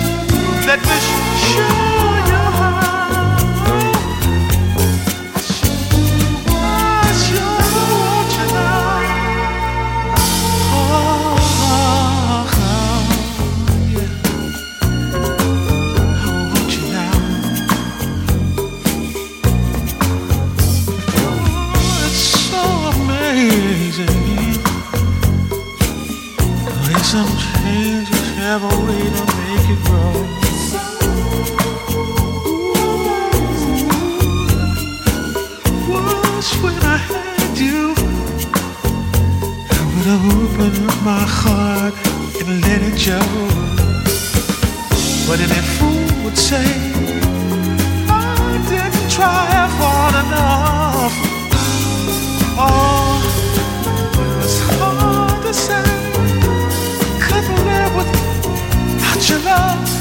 AORやブラジルもの好きにもオススメなアダルトで洗練された傑作！